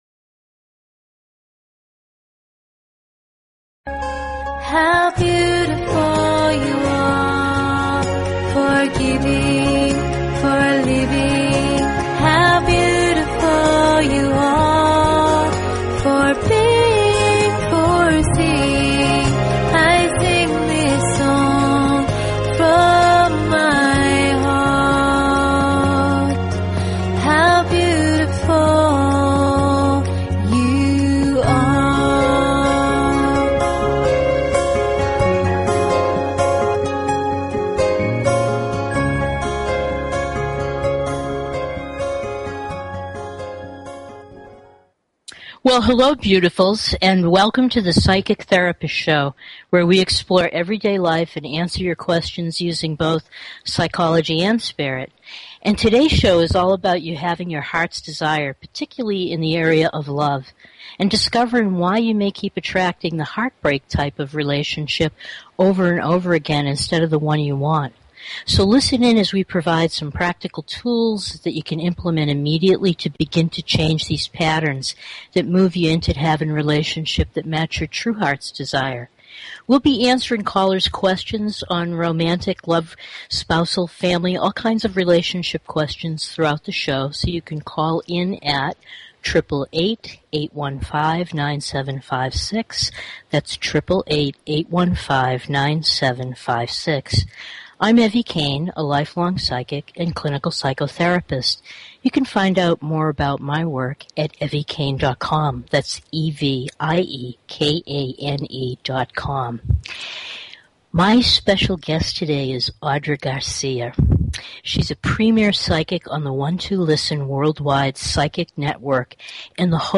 Talk Show Episode, Audio Podcast, Psychic_Therapist_Show and Courtesy of BBS Radio on , show guests , about , categorized as